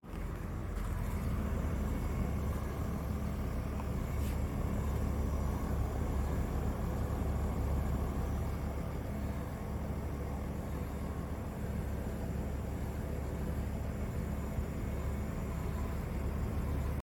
Bruit bizarre venant du bas de l'unité extérieure Hitachi
Il est difficile à décrire, mais il ressemble à un raclement et il est nécessaire de monter le son pour l'entendre.
Je suis désolé, mais on entend également le bruit du ventilateur, mais toutes les quelques secondes, ce bruit étrange se fait entendre.
Ça ressemble à " rhhhiuuu rhiuuuuu." on l'entend bien de la 1ere seconde à la 4eme seconde.
Après on entend en continue de manière identique sur toute la bande son le ventilateur qui tourne fort.
Les sons qui varient toutes les secondes viennent du compresseur.
bruit-bizarre-ue-hitachi.mp3